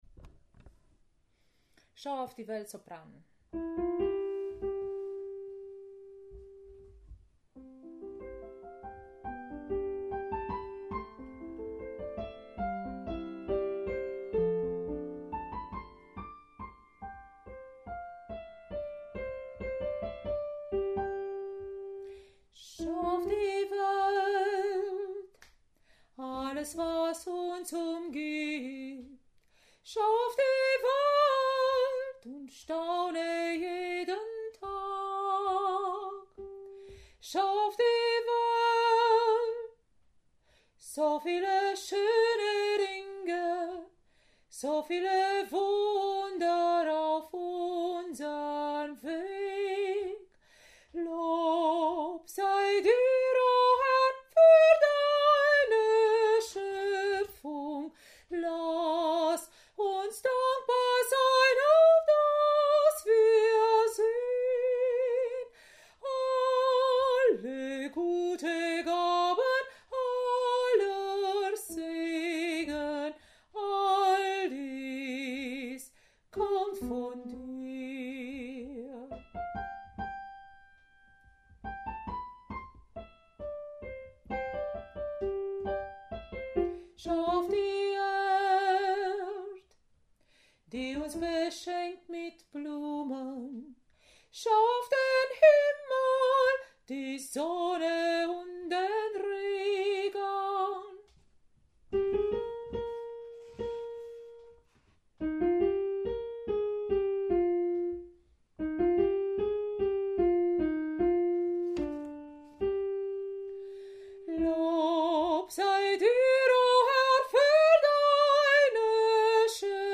Schau auf die Welt – Sopran